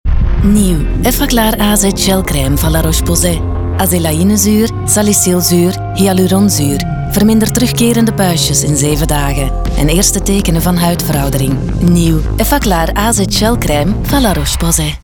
Vlaams
Commercieel, Natuurlijk, Speels, Vertrouwd, Warm
Commercieel